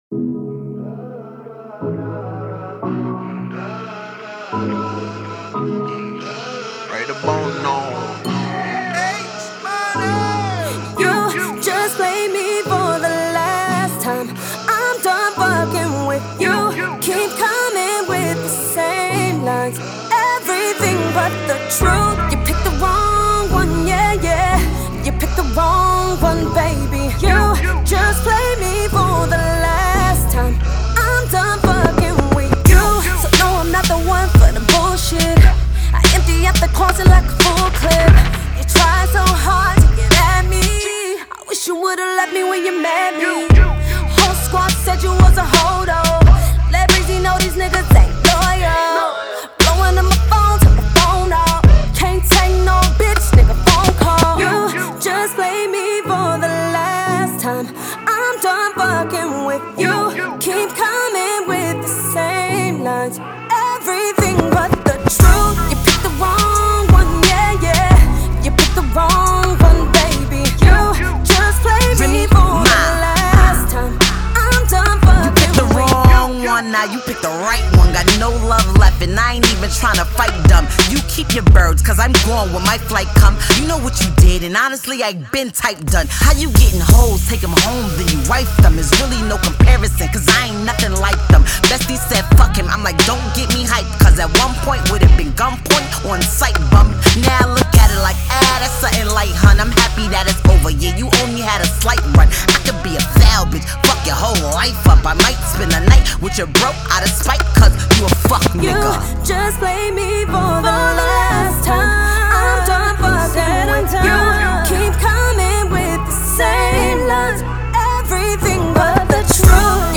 Over a dark but melodic beat